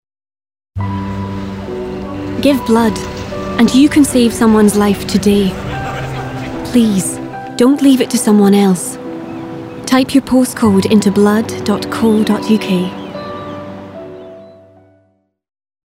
SCOTS. Smooth and calming to upbeat irreverence. Personable lass. A natural VO. Avid Muso.
Her accents range from Scottish East Coast, Edinburgh and Highlands to RP and Irish, and voice age ranging from teens to late 20s.